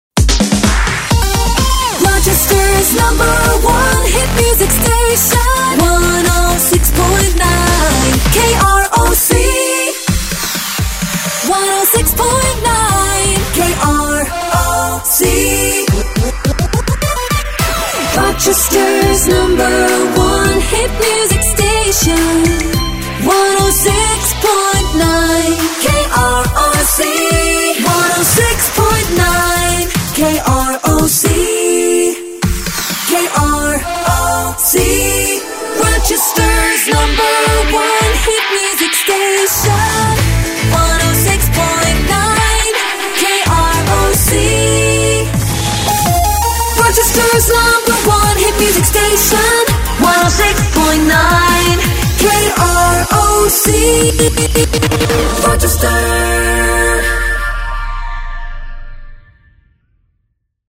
A powerful Top 40 package